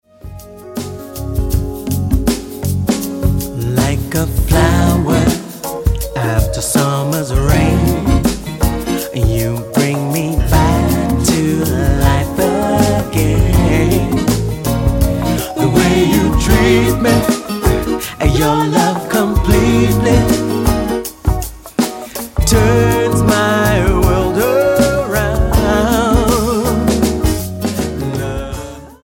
The British R&B gospel group Back in December 1989
Style: Jazz